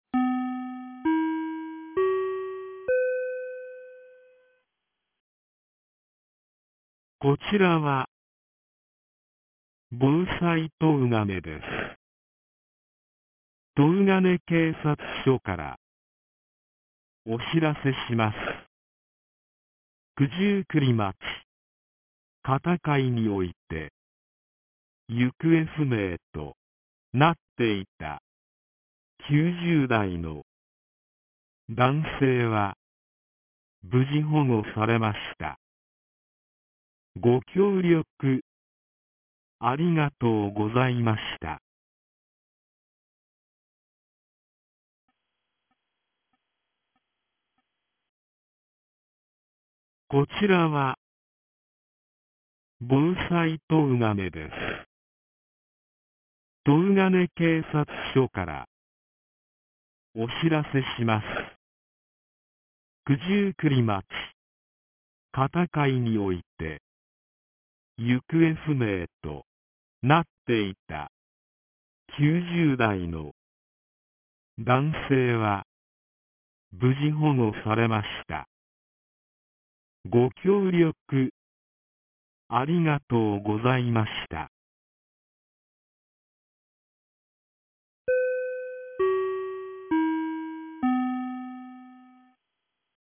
2026年04月08日 17時41分に、東金市より防災行政無線の放送を行いました。